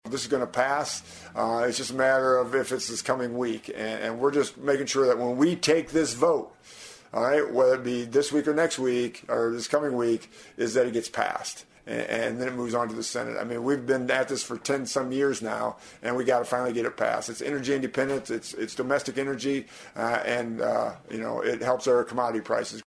FEENSTRA SAYS THAT MISSED DEADLINE DOES NOT MEAN A VOTE WON’T TAKE PLACE: